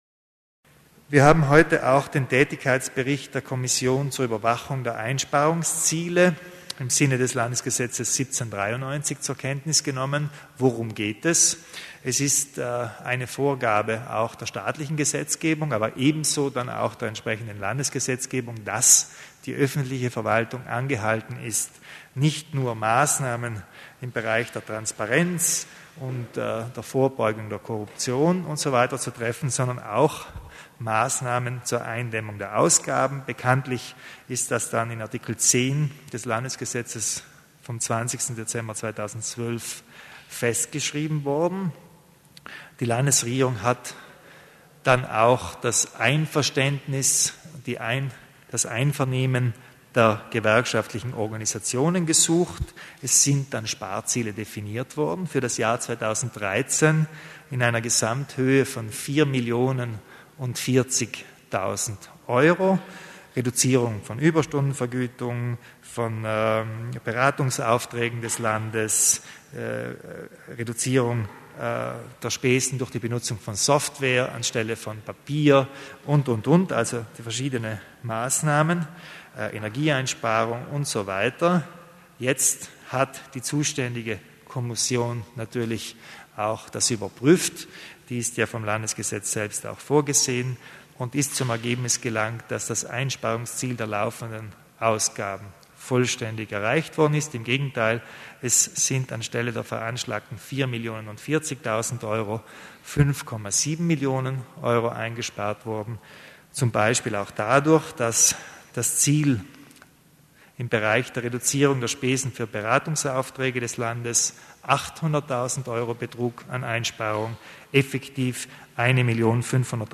Landeshauptmann Kompatscher erläutert die Maßnahmen im Bereich Einsparungen